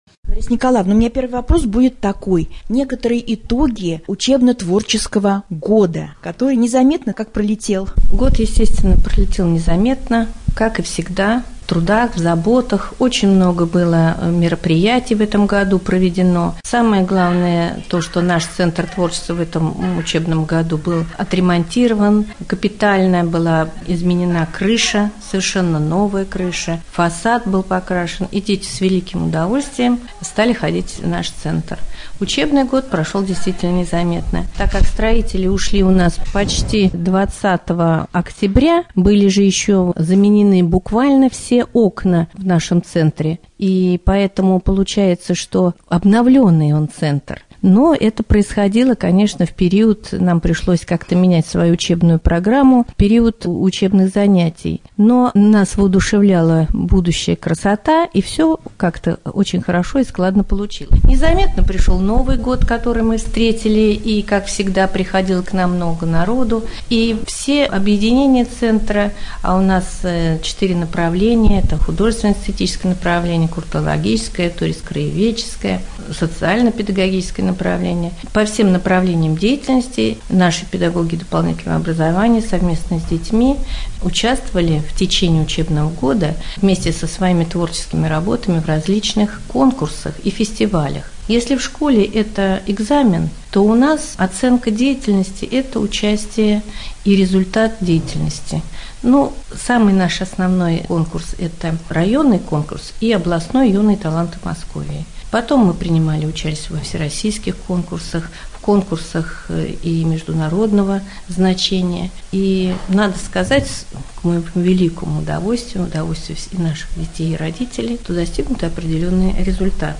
Беседу